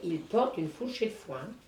Langue Maraîchin
Catégorie Locution